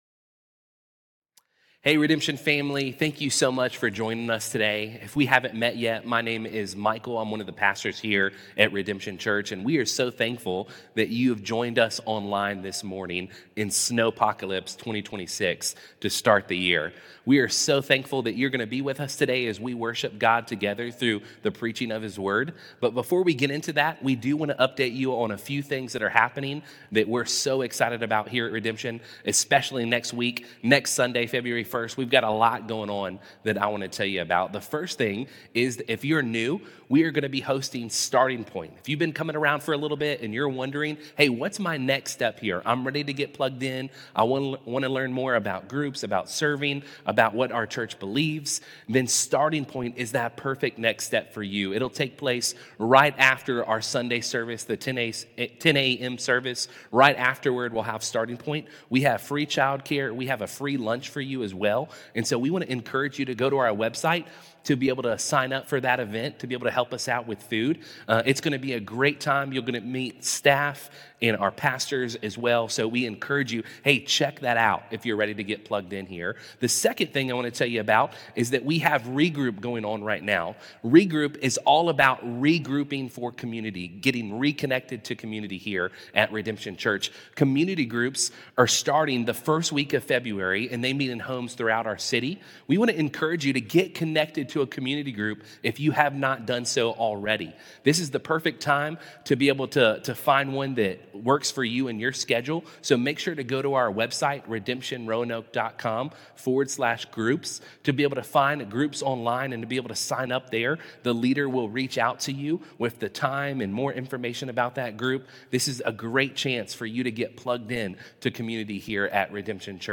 This Sunday, we gathered virtually for a special message. We studied Hebrews 2:5–10 to learn why angels, though real and powerful, are servants in God’s plan.